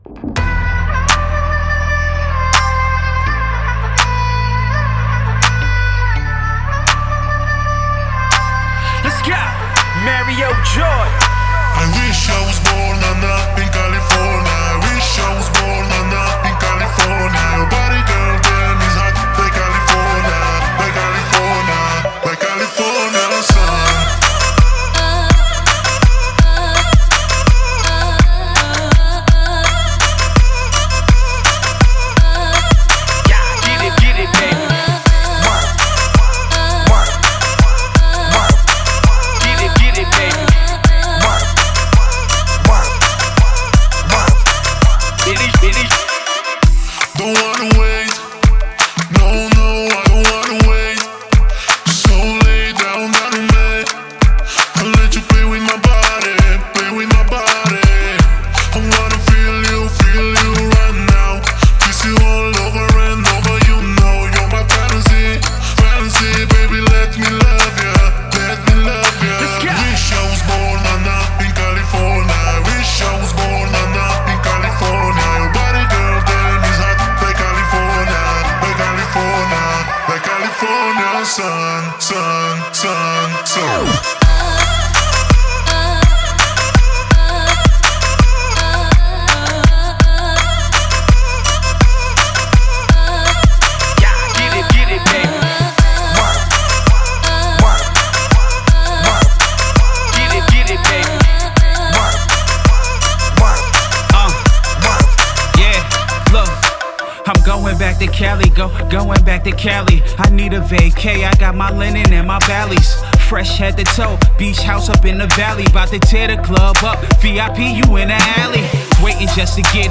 Музыка для тренировок